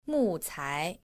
• mùcái